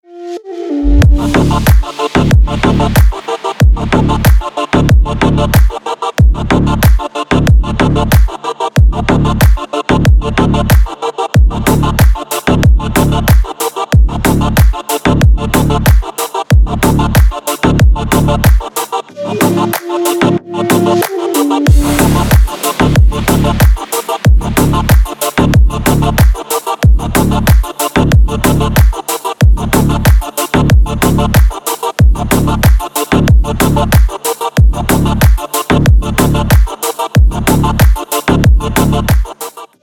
Клубные рингтоны